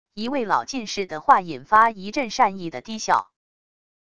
一位老进士的话引发一阵善意的低笑wav音频